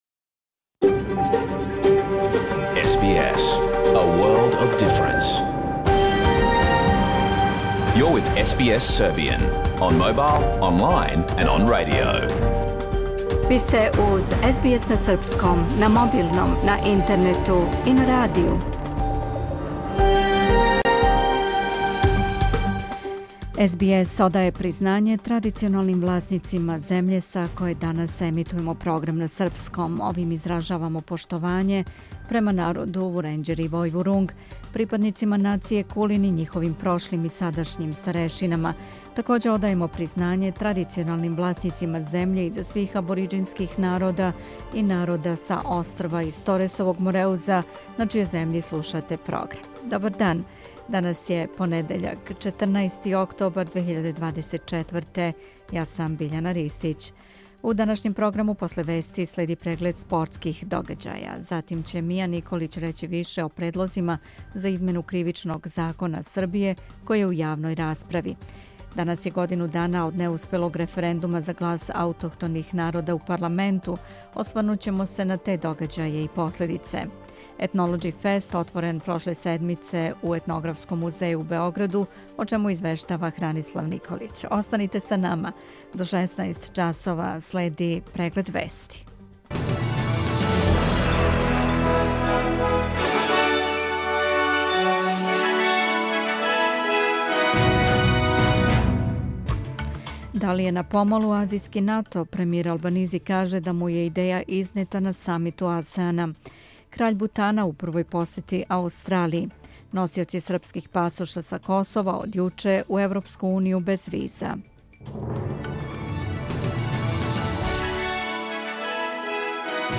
Програм емитован уживо 14. октобра 2024. године
Уколико сте пропустили данашњу емисију, можете је послушати у целини као подкаст, без реклама.